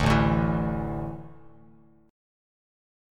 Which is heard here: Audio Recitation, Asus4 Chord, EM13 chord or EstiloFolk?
Asus4 Chord